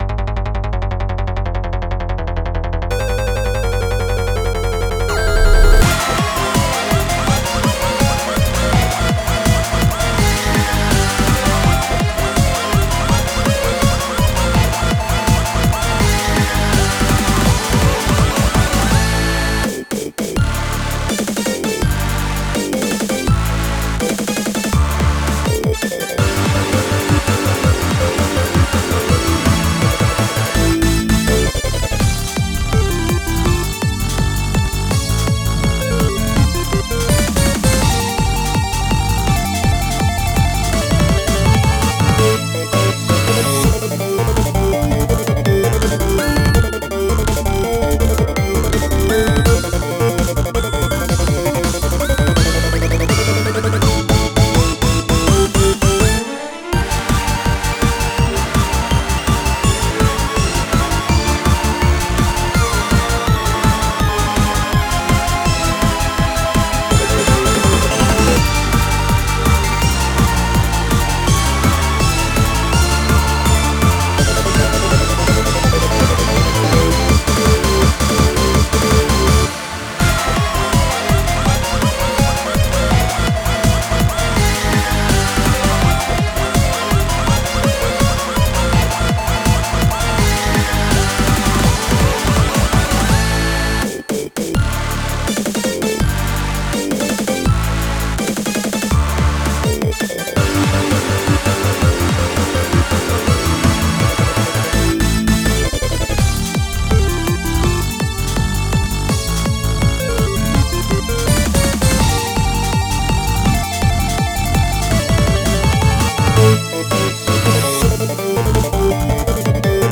はげしい FREE BGM